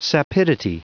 Prononciation du mot sapidity en anglais (fichier audio)
Prononciation du mot : sapidity